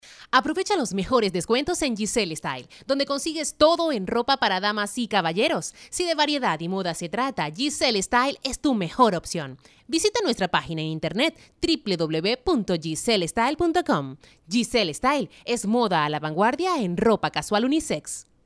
Locutora profesional, voz marca, voz institucional, productora, narradora de noticias.
Sprechprobe: Industrie (Muttersprache):